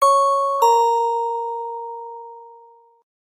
SMS_Dingdong.ogg